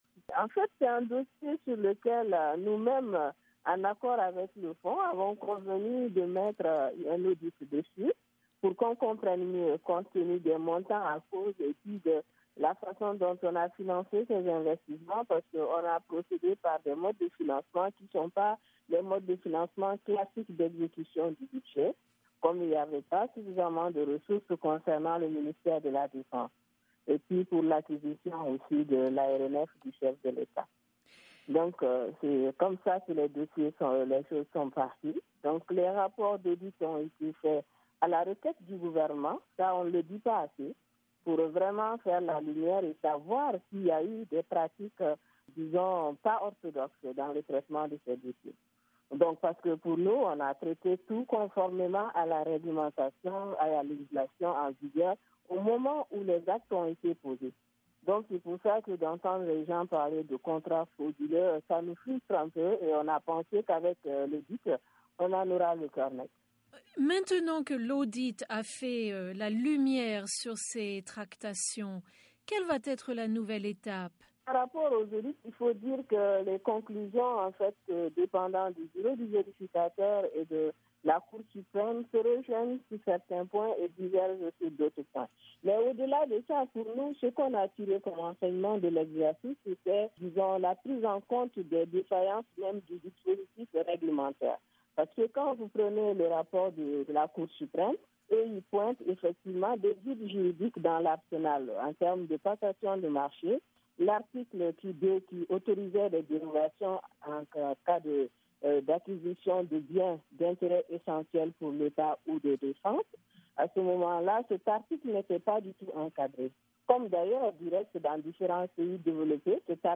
Ecoutez la ministre Bouare Fily Sissoko.mp3
Dans une interview avec la Voix de l’Amérique (VOA), elle a évoqué les progrès accomplis.